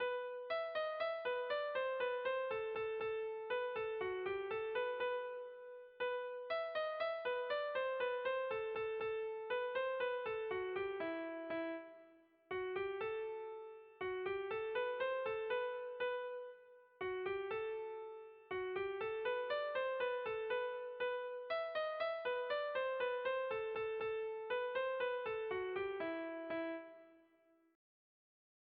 Zortziko handia (hg) / Lau puntuko handia (ip)
A-A2-B-A2